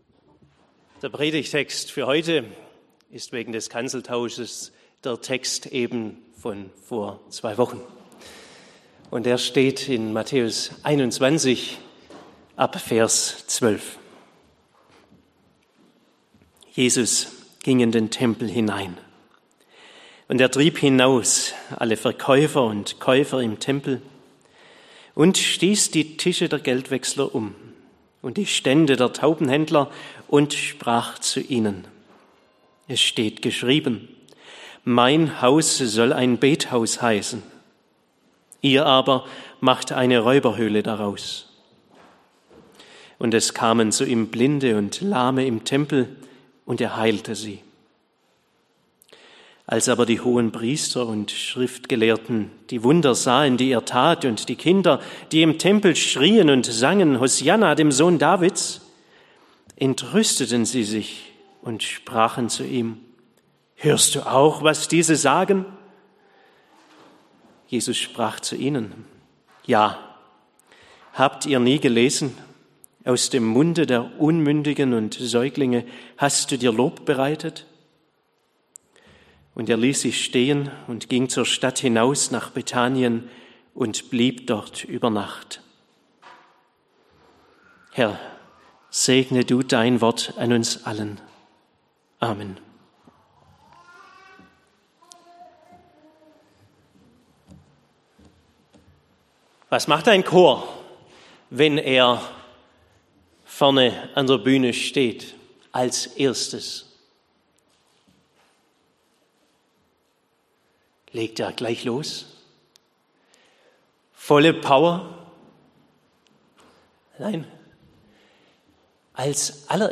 Von enttäuschter Hoffnung. Predigt